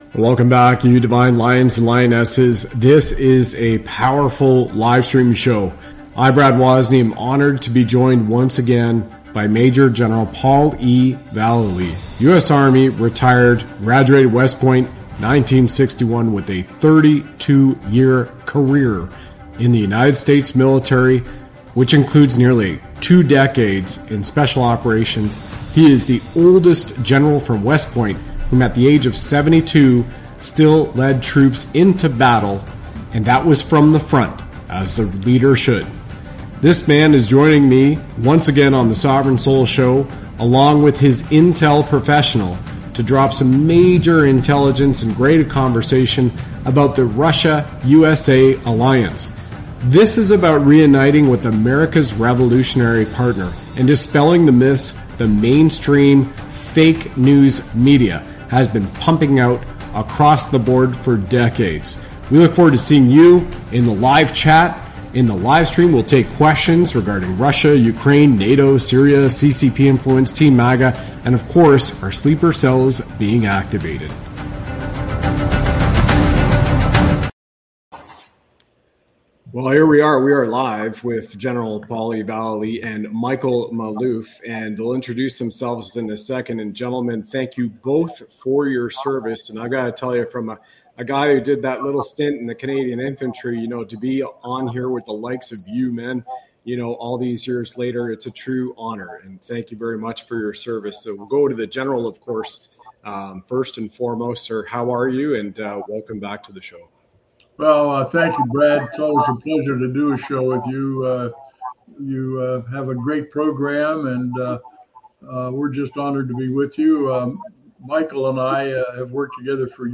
They discuss topics such as the Russia-USA alliance, the influence of fake news, and various global conflicts. The show also includes a live chat where viewers can ask questions about Russia, Ukraine, NATO, Syria, and more.